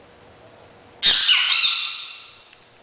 Son chant trisyllabique permet de l'identifier facilement.
Son chant peut toutefois être bisyllabique ou monosyllabique.
Les chants varient suivant les régions ce qui peut expliquer la variation des noms donnés localement à l'espèce.
Pitangus_sulphuratus_sound2.ogg